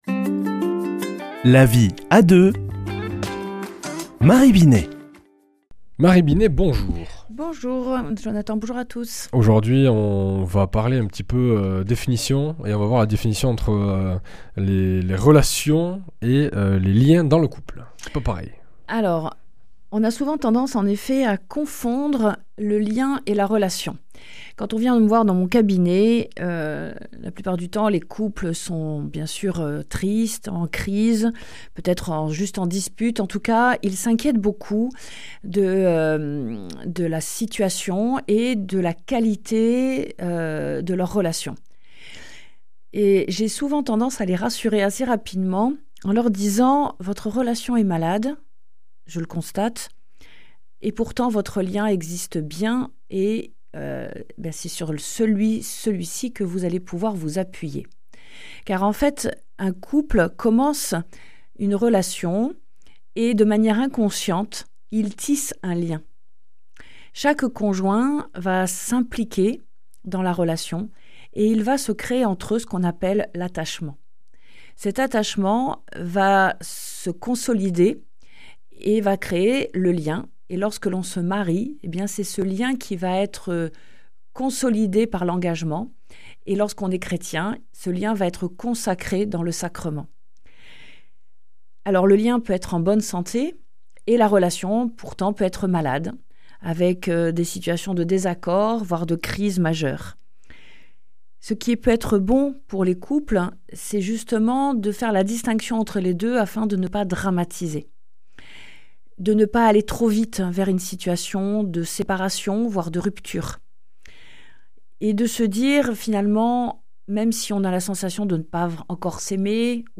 mardi 6 mai 2025 Chronique La vie à deux Durée 4 min